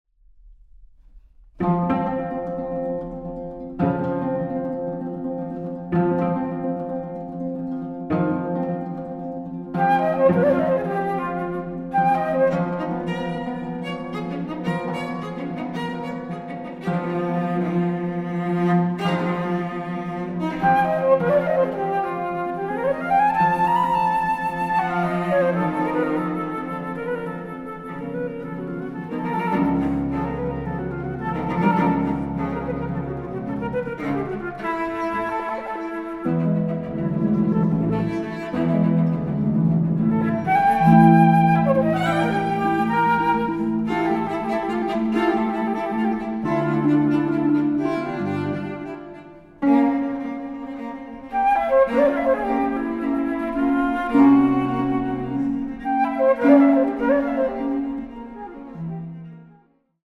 Flöte
Harfe